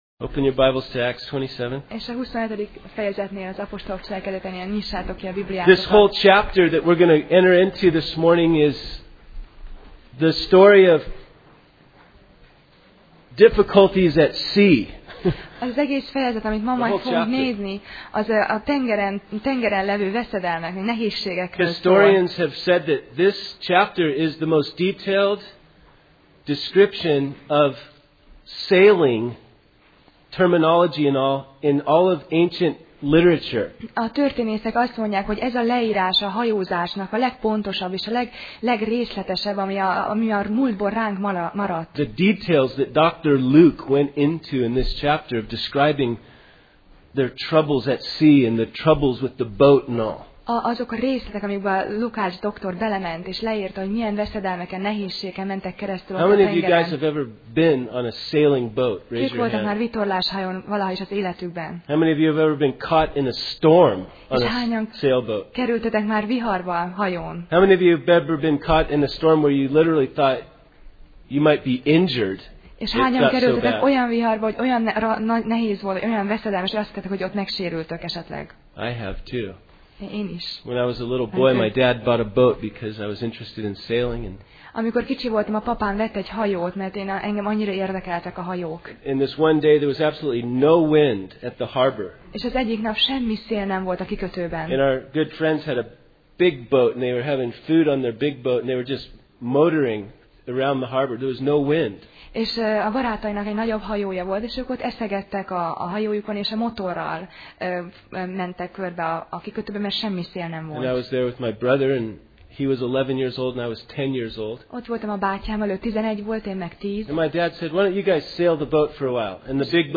Sorozat: Apostolok cselekedetei Passage: Apcsel (Acts) 27:1-44 Alkalom: Vasárnap Reggel